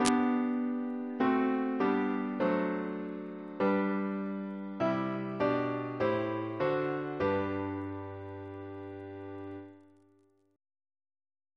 Single chant in G Composer: Jonathan Battishill (1738-1801) Reference psalters: ACB: 23; CWP: 3; OCB: 307; PP/SNCB: 44; RSCM: 169